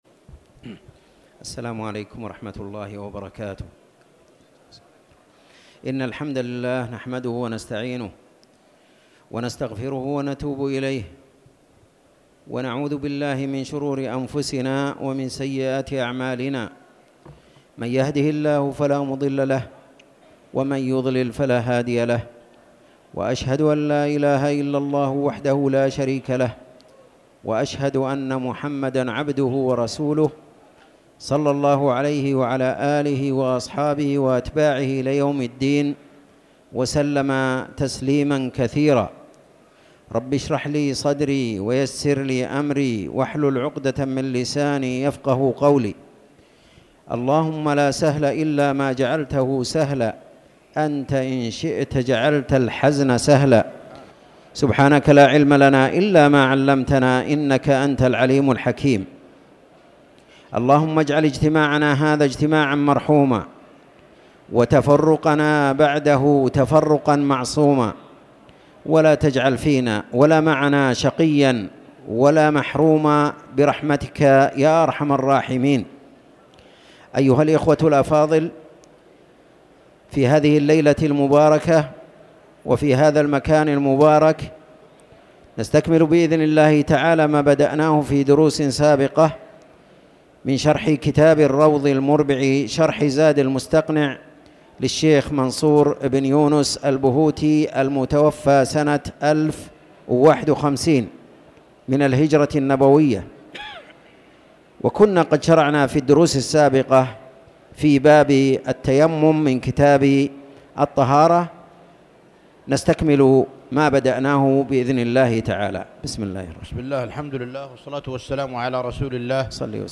تاريخ النشر ١٣ جمادى الآخرة ١٤٤٠ هـ المكان: المسجد الحرام الشيخ